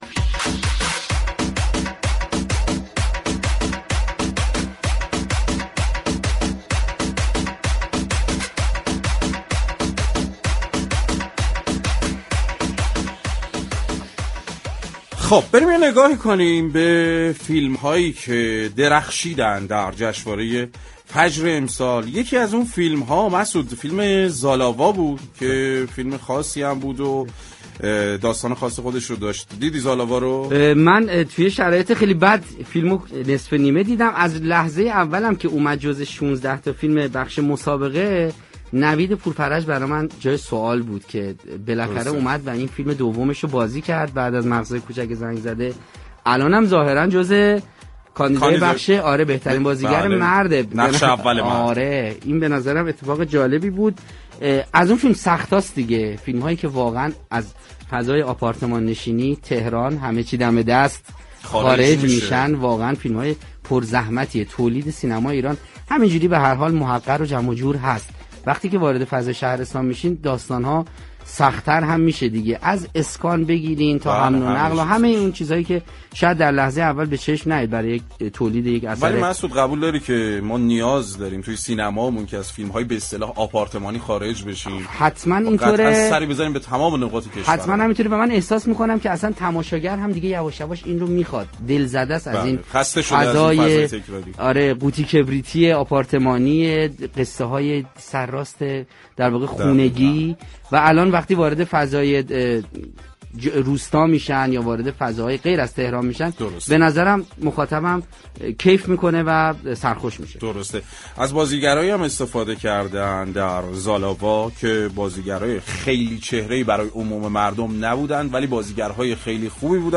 به گزارش پایگاه اطلاع رسانی رادیو تهران، پوریا رحیمی‌سام بازیگر فیلم سینمایی زالاوا مهمان تلفنی برنامه صحنه 20 بهمن درباره ایفای نقش در این اثر گفت: من از پنج سال پیش در جریان این فیلمنامه بودم و آشنایی من با ارسلان امیری و آیدا پناهنده به سال‌های خیلی دور برمی‌گردد.